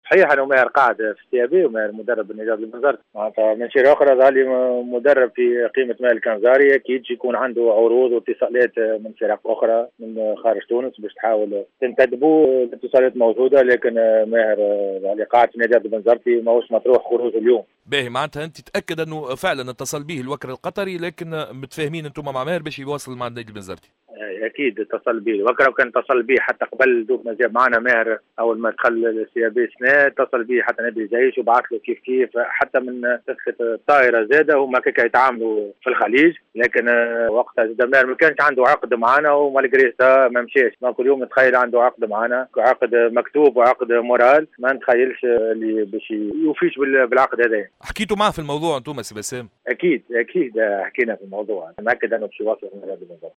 déclaration